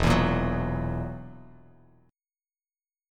Esus2b5 chord